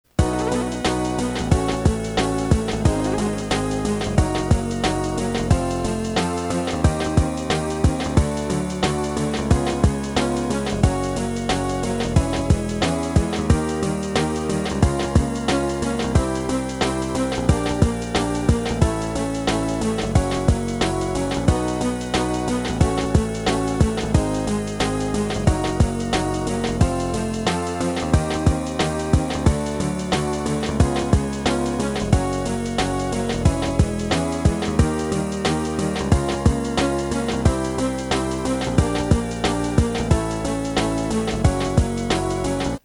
Index of /phonetones/unzipped/Sony Ericsson/T280/Alarm sound
ALARM 03.mp3